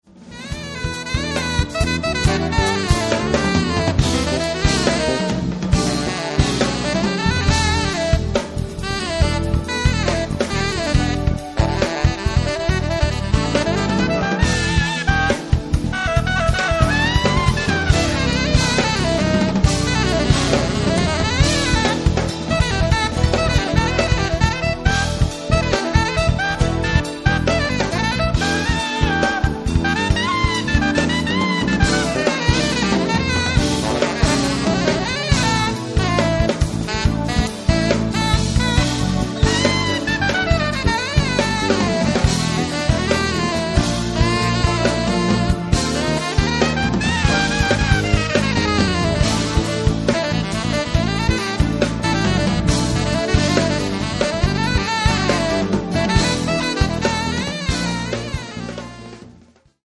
basso elettrico
sax
chitarre
tastiere
batteria
That's fusion